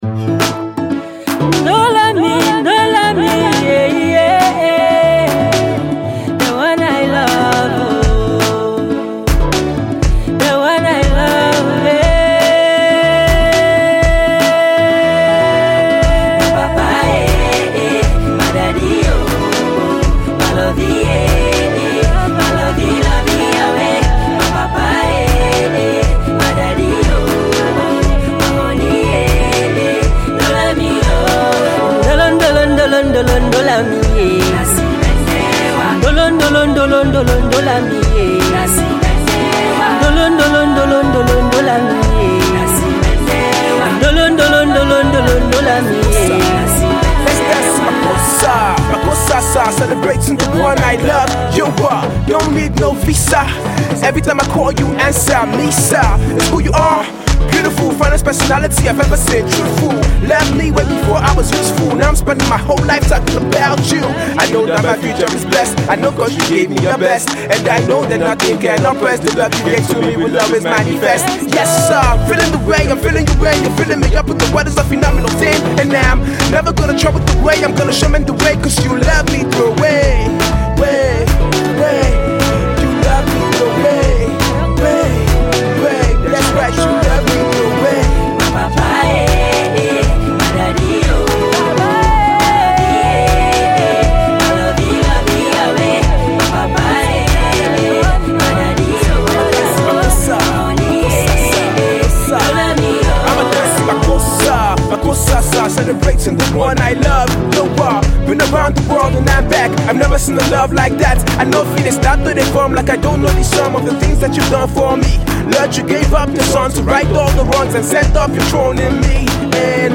single